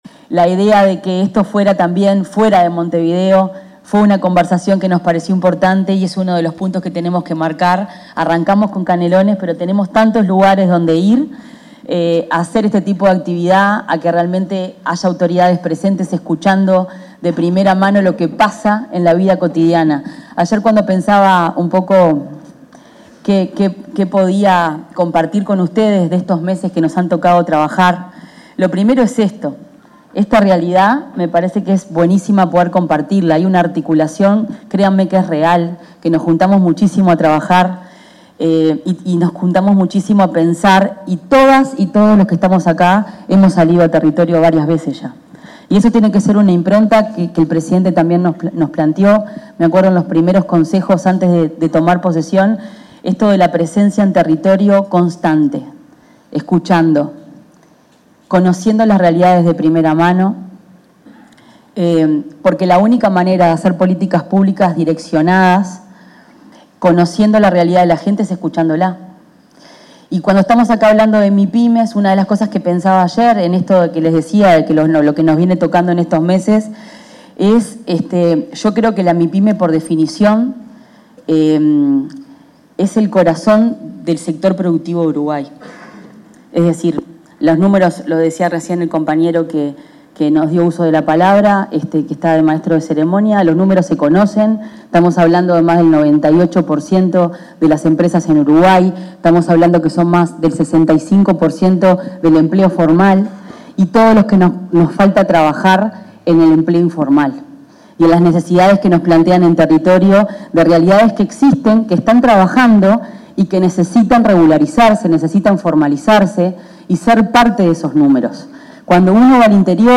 Palabras de autoridades en el Día Nacional de las Mipymes
Palabras de autoridades en el Día Nacional de las Mipymes 13/08/2025 Compartir Facebook X Copiar enlace WhatsApp LinkedIn Durante el acto conmemorativo del Día Nacional de las Mipymes, se expresaron la ministra de Industria, Energía y Minería, Fernanda Cardona; su par de Economía y Finanzas, Gabriel Oddone, y el presidente de la Agencia Nacional de Desarrollo, Juan Ignacio Dorrego.